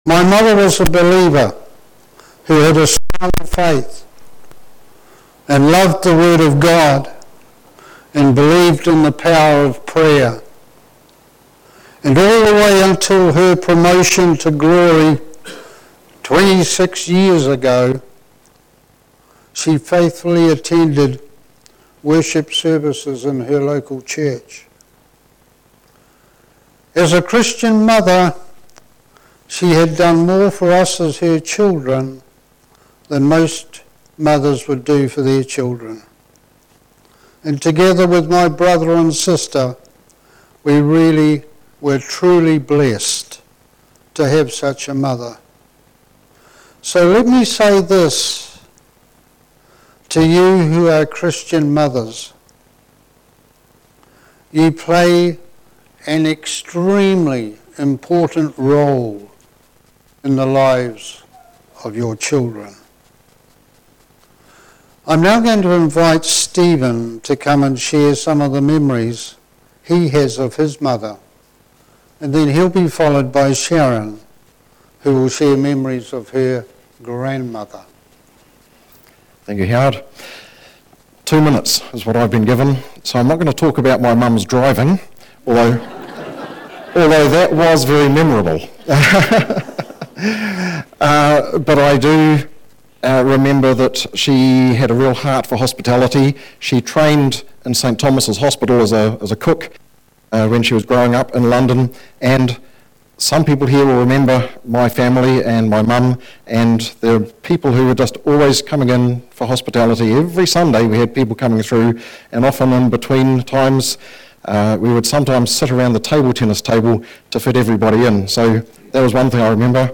Mother’s Day Sermon